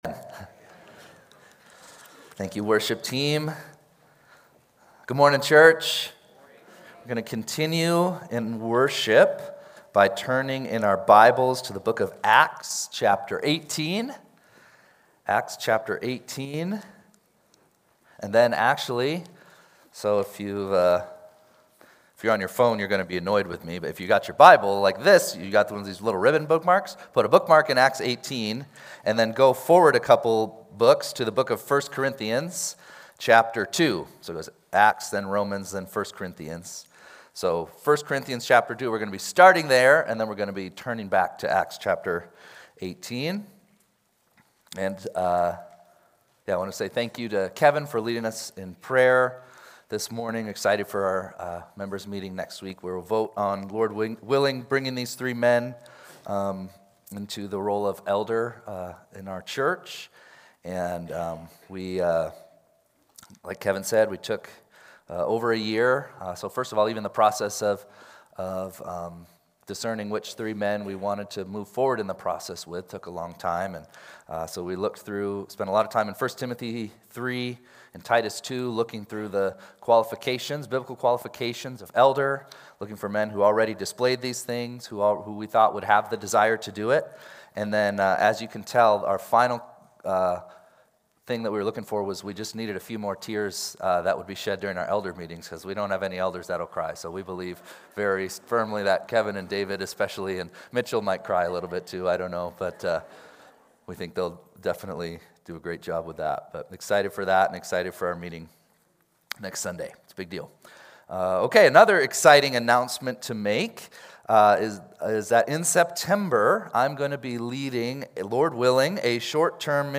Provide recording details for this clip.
2-8-26-Sunday-Service.mp3